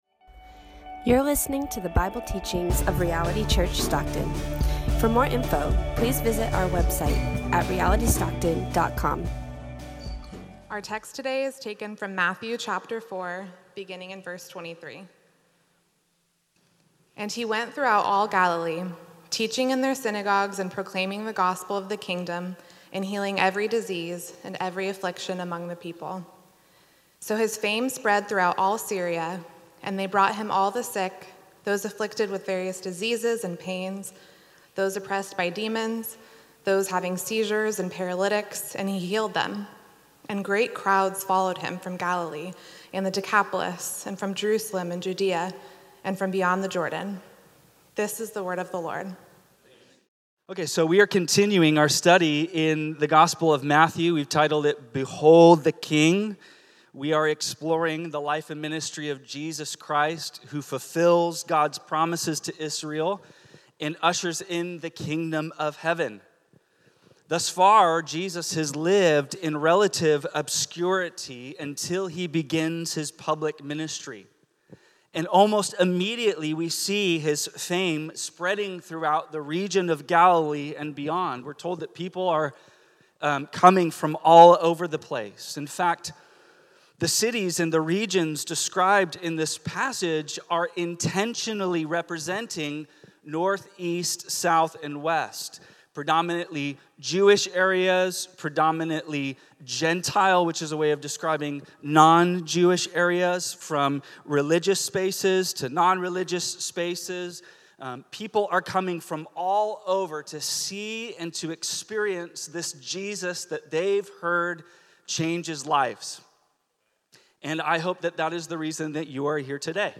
Bible teachings from Reality Church Stockton.